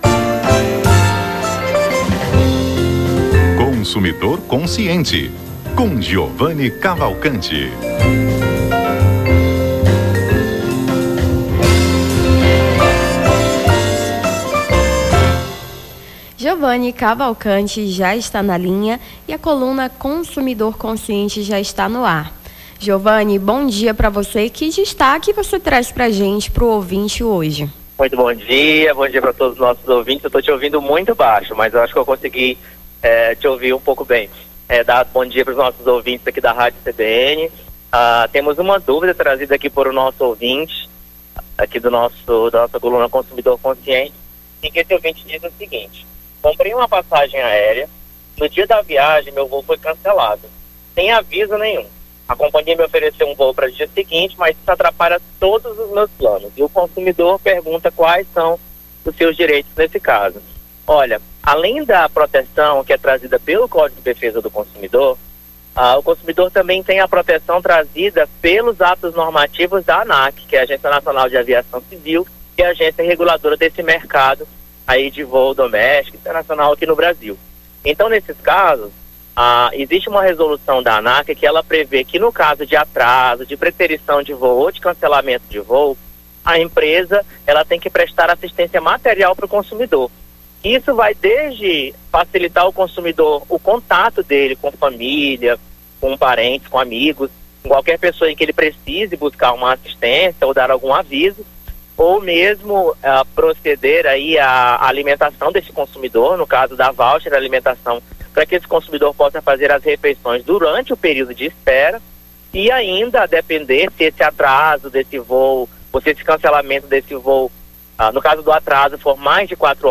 Consumidor Consciente: advogado tira dúvidas sobre direito do consumidor